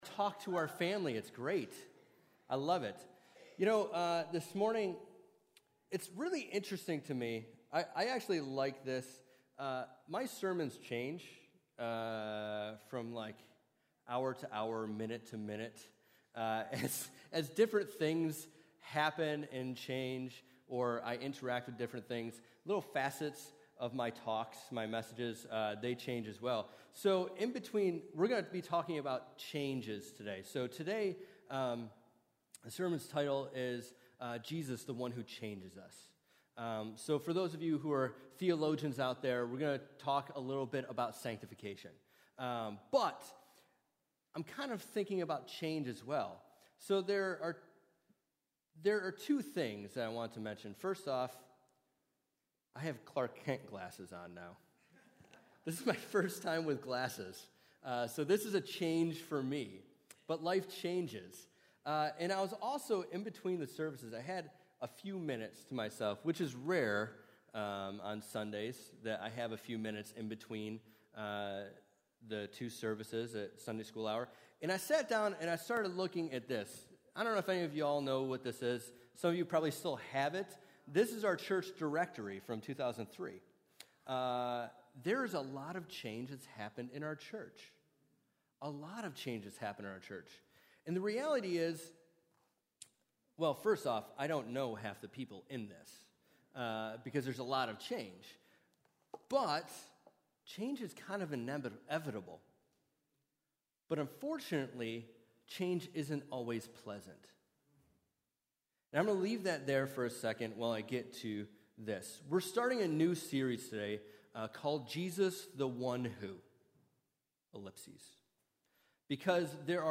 Sermons | Syracuse Alliance Church